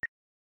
Button02.wav